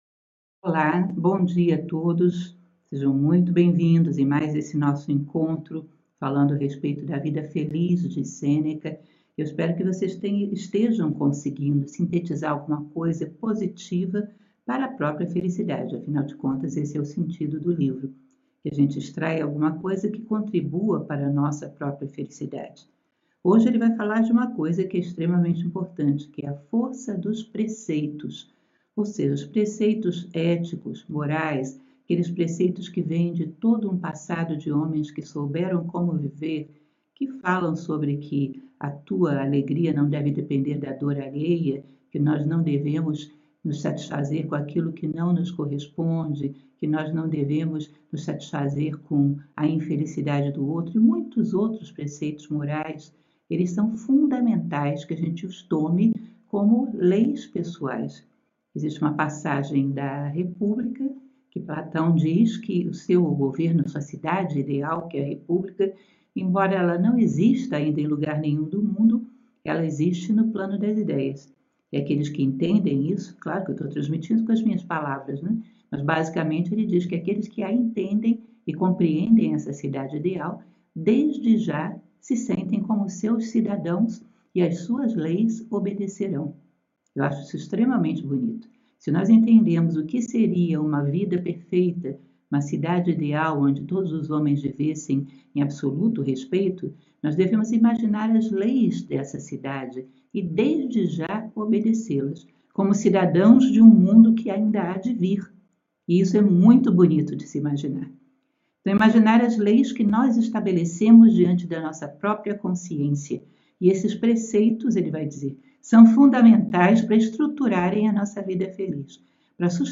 LEITURA COMENTADA